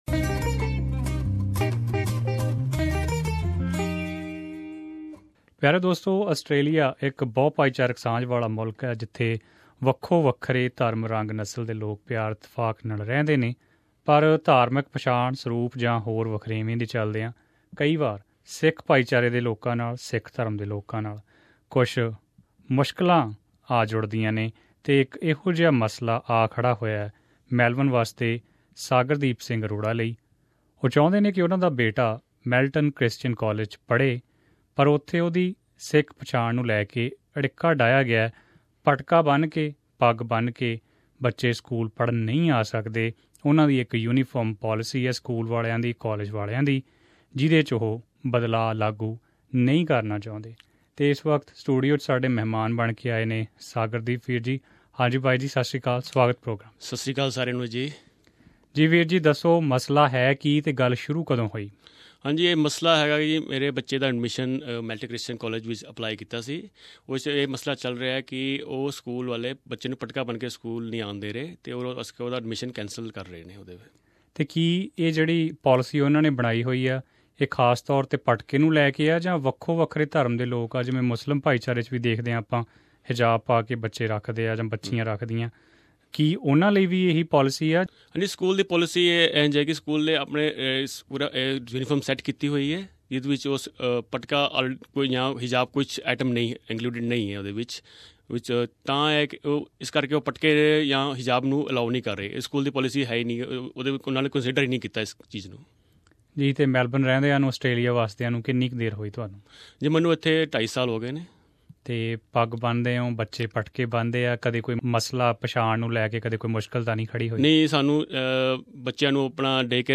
In an interview with SBS Punjabi